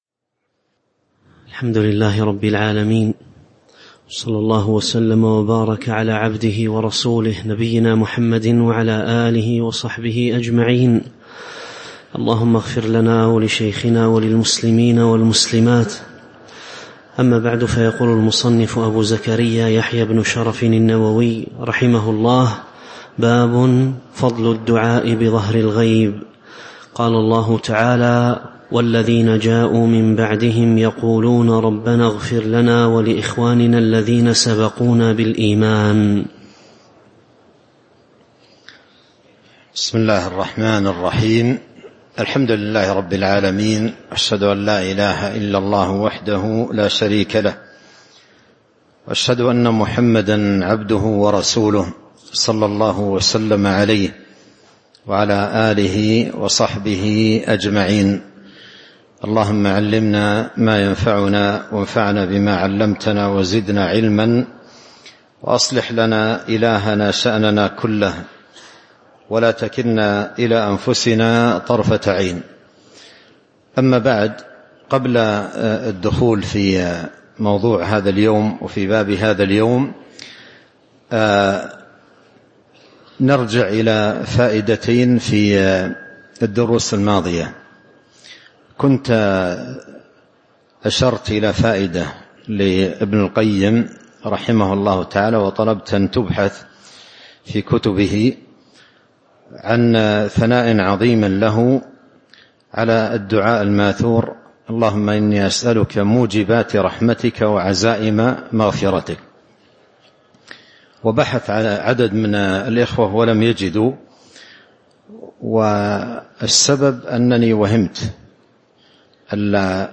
تاريخ النشر ٩ رمضان ١٤٤٥ هـ المكان: المسجد النبوي الشيخ